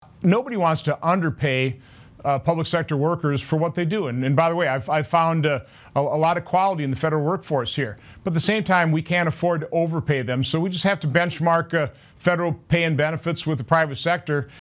Senator Johnson gave these answers during an interview on Wednesday, Nov. 12, with WBAY-TV.